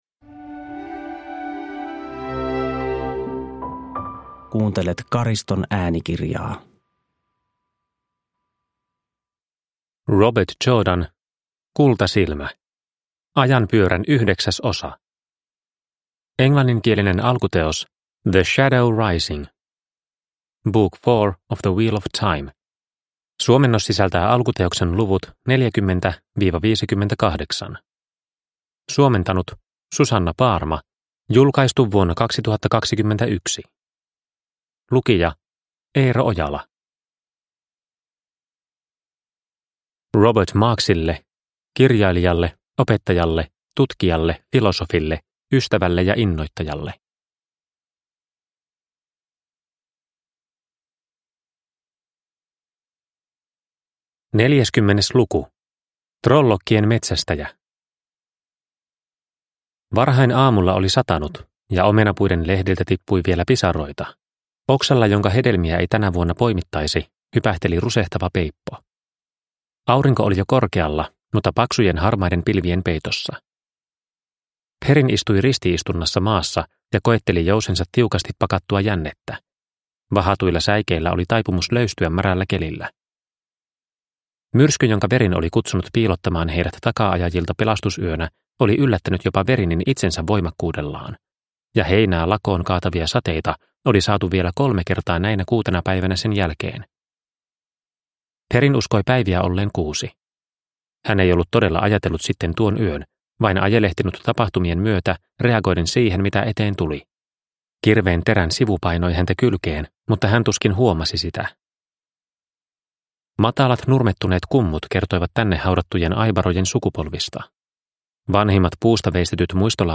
Kultasilmä – Ljudbok – Laddas ner